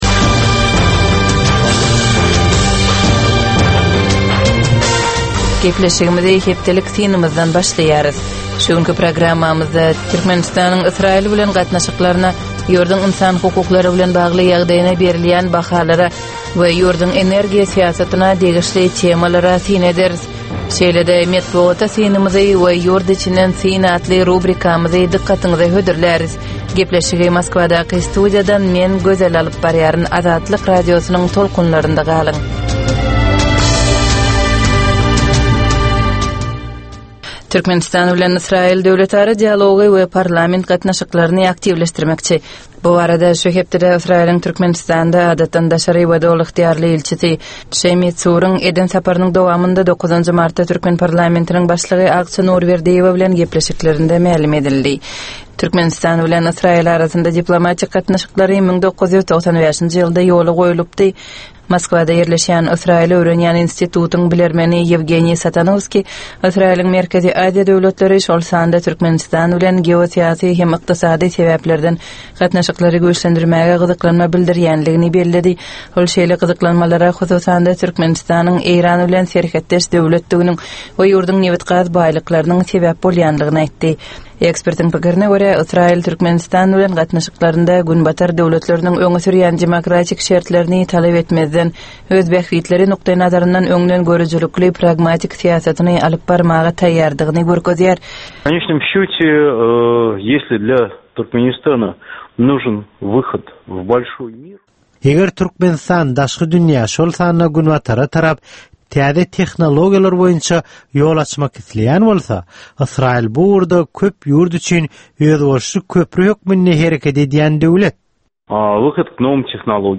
Tutuş geçen bir hepdäniň dowamynda Türkmenistanda we halkara arenasynda bolup geçen möhüm wakalara syn. 25 minutlyk bu ýörite programmanyň dowamynda hepdäniň möhüm wakalary barada gysga synlar, analizler, makalalar, reportažlar, söhbetdeşlikler we kommentariýalar berilýär.